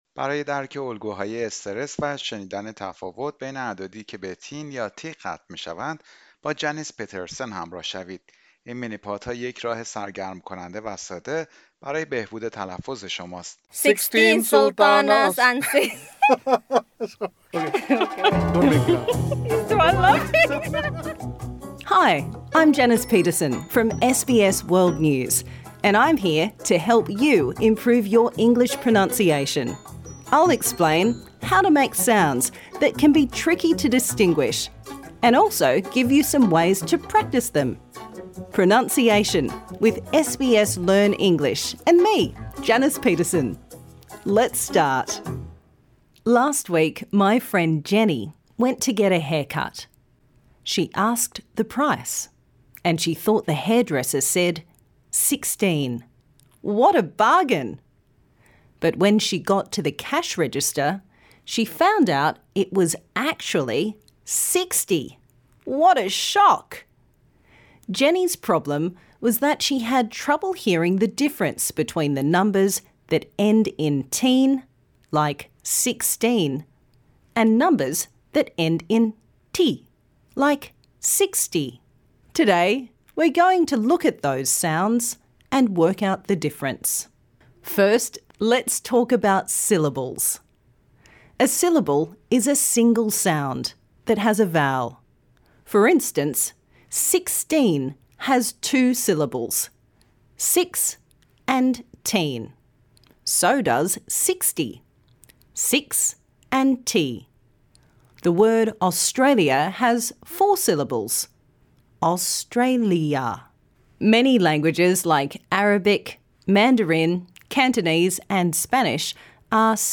Stress syllables - Pronunciation
Key Points Learning Objectives: understanding stress patterns and practicing the difference between numbers ending in -teen or -ty L1 Influence: many language learners struggle with ‘teen’ and ‘ty’ because English is stress timed, not syllable timed. Text for Practise : I went to the store and bought thirteen tomatoes for thirty dollars, fourteen fish for forty dollars, fifteen forks for fifty dollars, etc. Minimal Pairs : ‘teen’ is the stressed syllable - it is long and clear and the /t/ is clearly pronounced: thirteen, fourteen... ‘ty’ is the unstressed syllable- it is short and quick and the ‘t’ is pronounced /d/: thirty, forty, fifty...